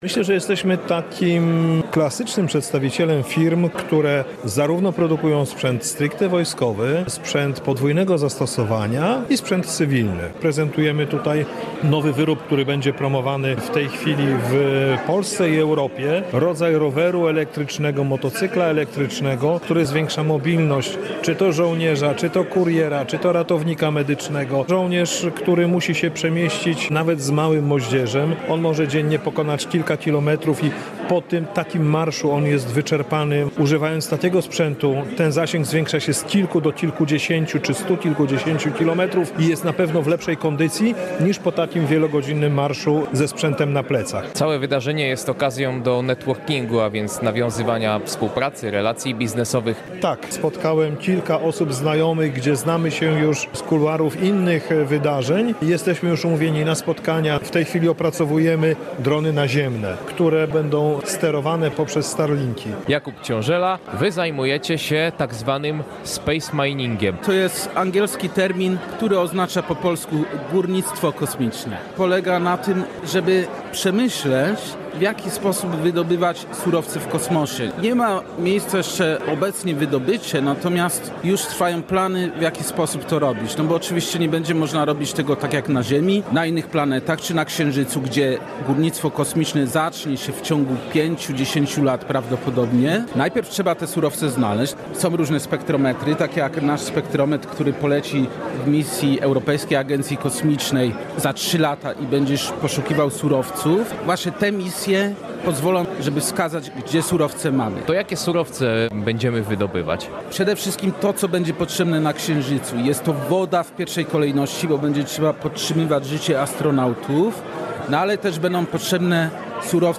SpaceShield Summit w Stalowej Woli – międzynarodowy kongres technologii kosmicznych i obronnych • Relacje reporterskie • Polskie Radio Rzeszów